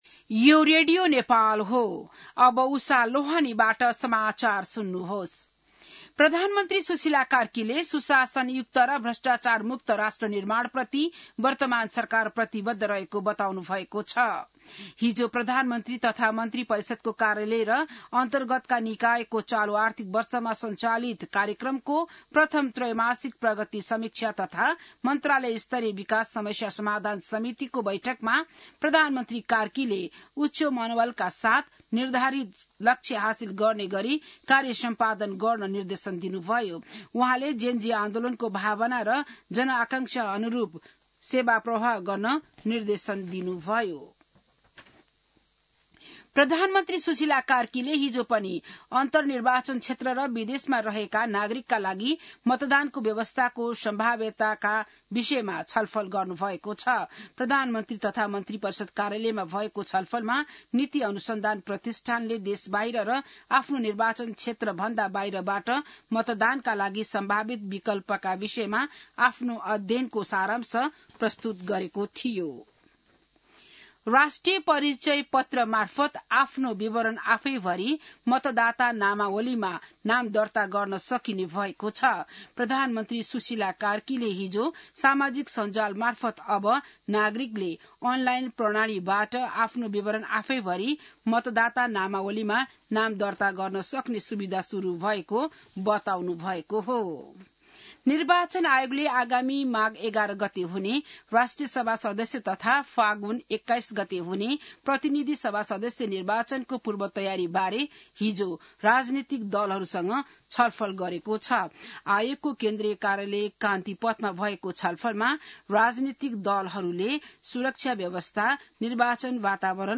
बिहान १० बजेको नेपाली समाचार : २८ कार्तिक , २०८२